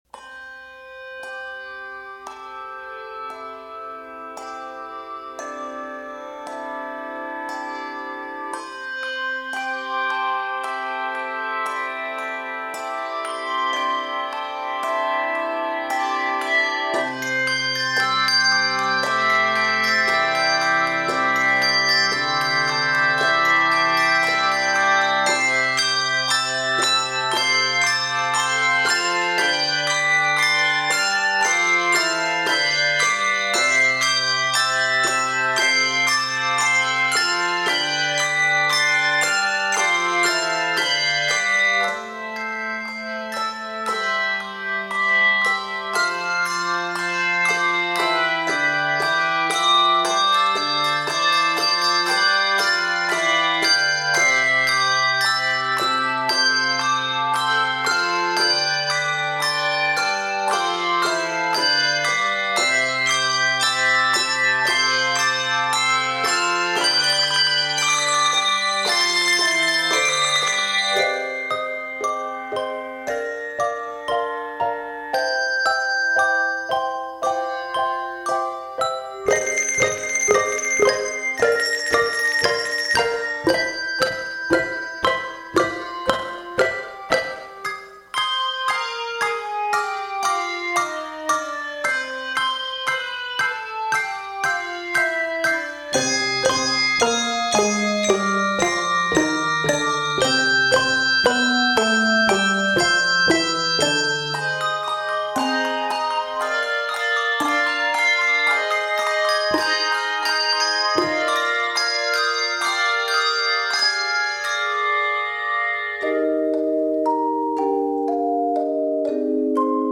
Keys of C Major and Eb Major.